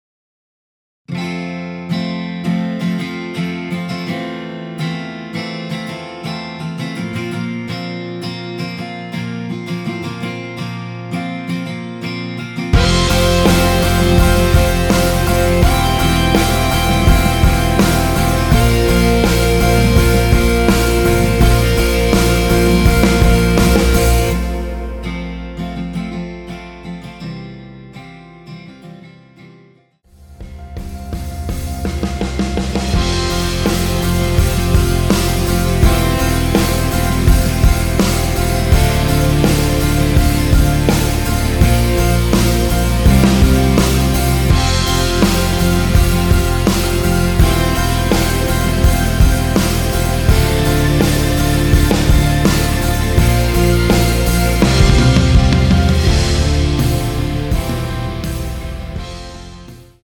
여성분이 부르실 수 있는 키의 MR입니다.
원키에서(+5)올린 MR입니다.
앞부분30초, 뒷부분30초씩 편집해서 올려 드리고 있습니다.